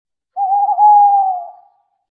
Tawny Owl Hooting Sound Button - Free Download & Play
Bird Sounds1,723 views